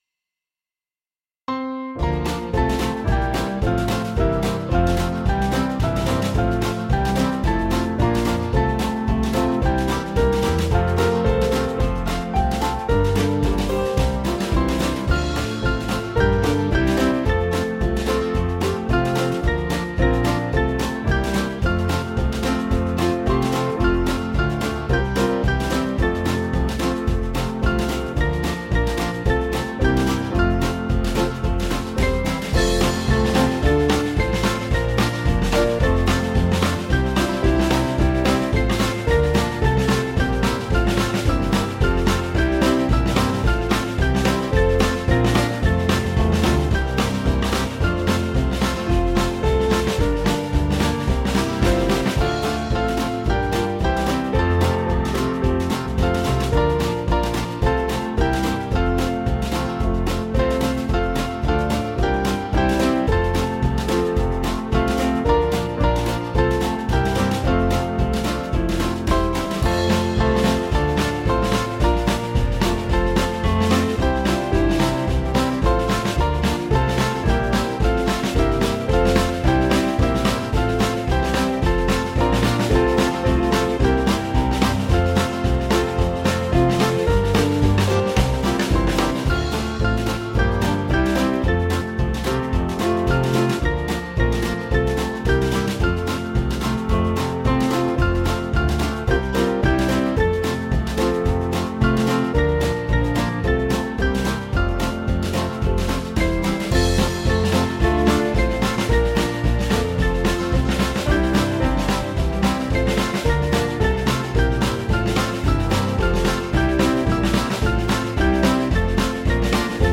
Small Band
(CM)   4/F-Gb 490kb